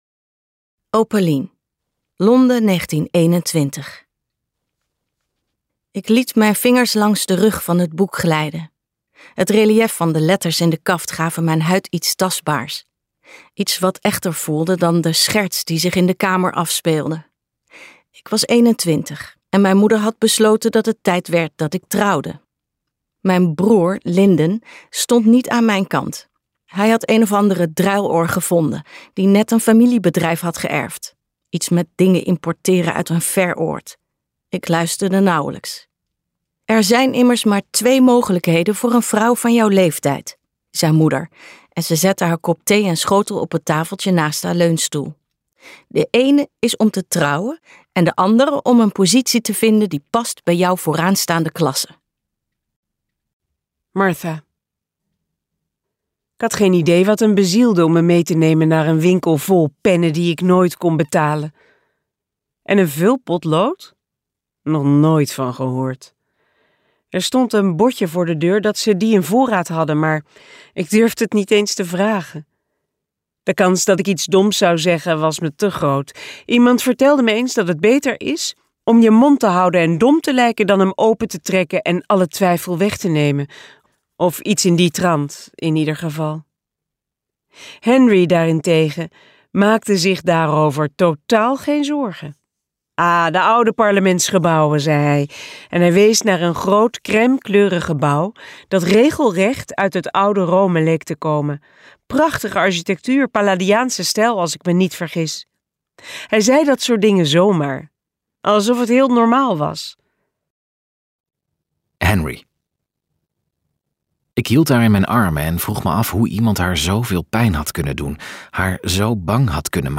Gratis leesfragment